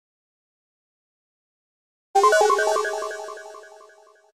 • Категория: Исчезновение, пропадание
• Качество: Высокое